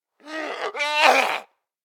spotted_8.ogg